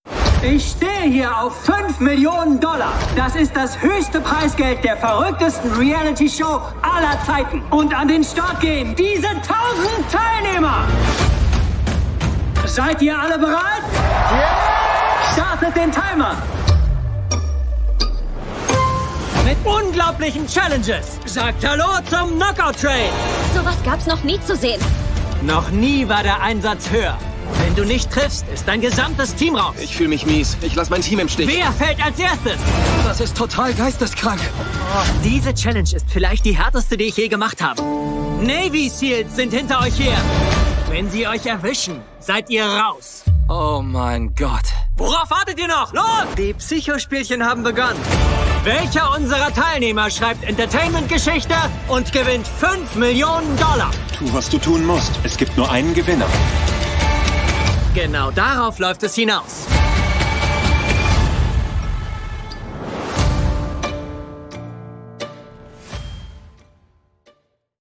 ★ Rolle: Mr. Beast (Hauptrolle)